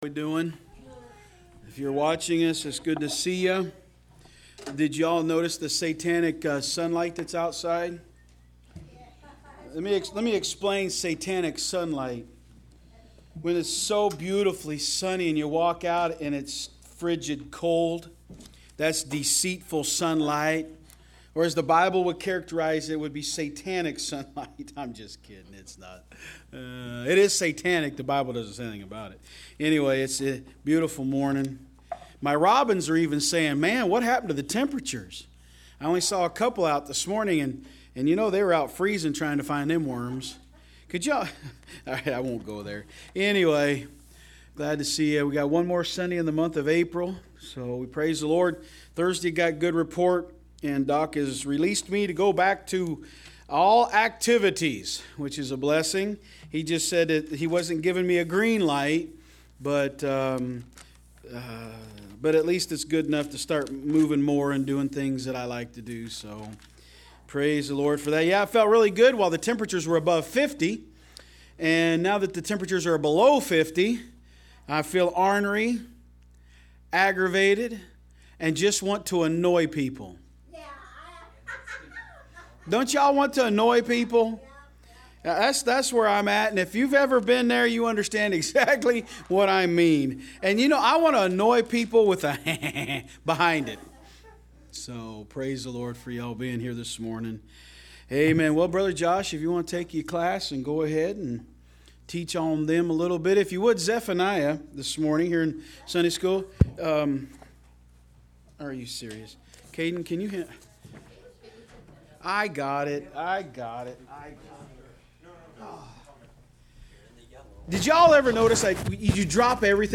Online Sermons – Walker Baptist Church